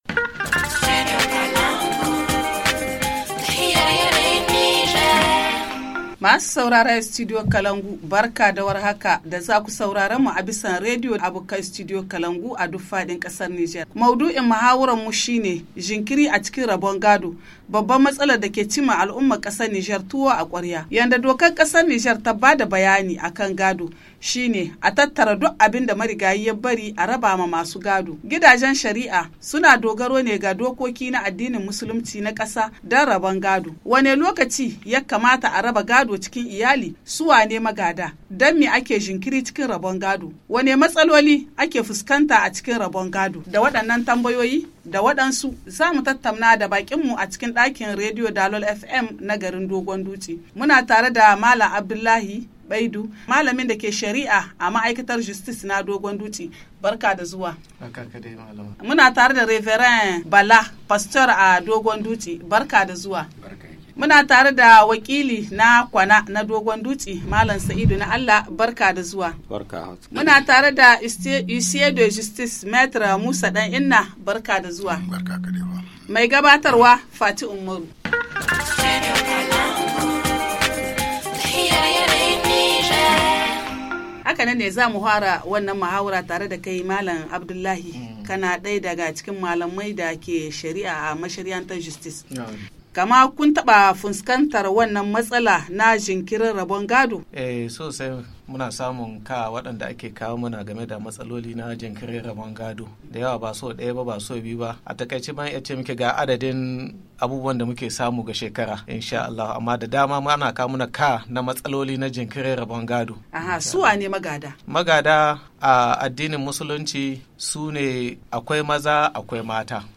Le forum en haoussa